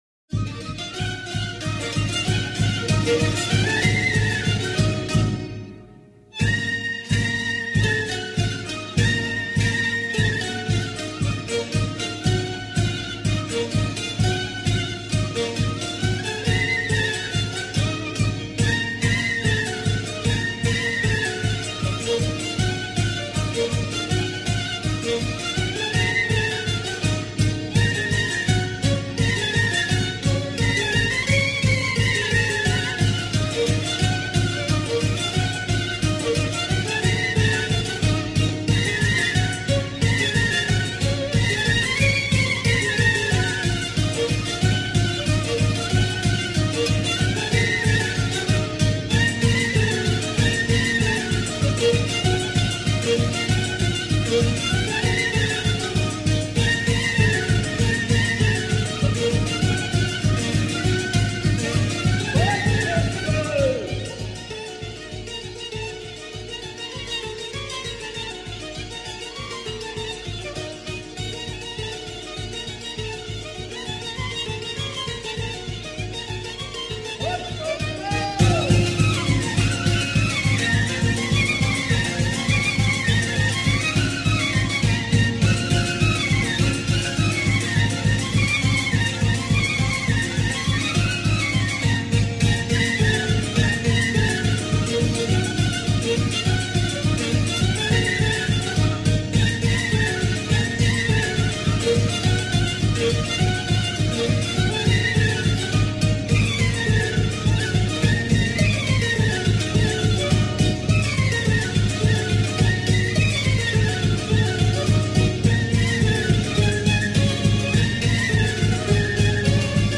навчити розрізняти звучання сопілки, скрипки, цимбалів;
Слухання. Троїсті музики
Зараз ми послухаємо ансамбль музичних інструментів, який у народі називають троїстими музиками.
23_Troisti_muziki.mp3